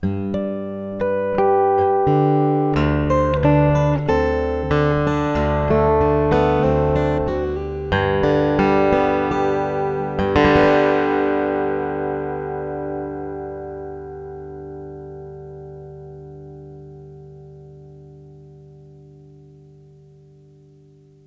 I played each clip separately, so there are plenty of performance variations. The guitar is a Martin OM-18GE with a K&K Pure Western pickup.
Subtle Acoustic.
Subtle-Acoustic.wav